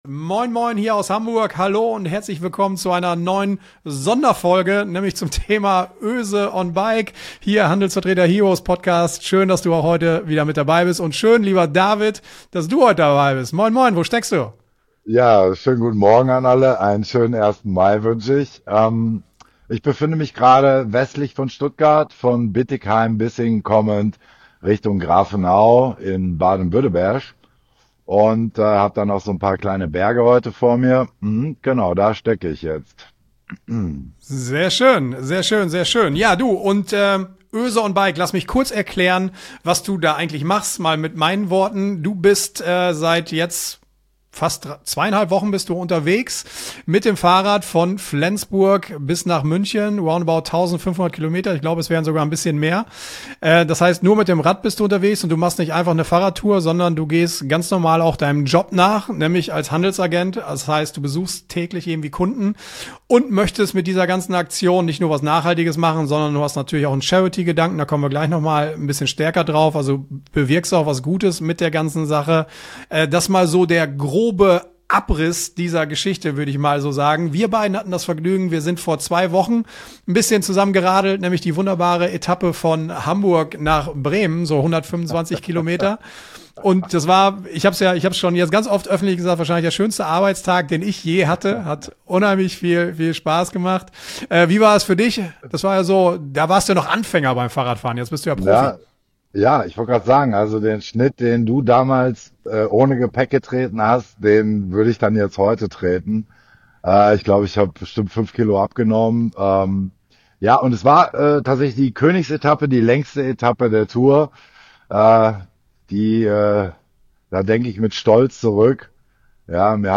EXPERTENTALK